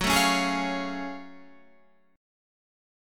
F#mM9 chord {x 9 7 10 9 9} chord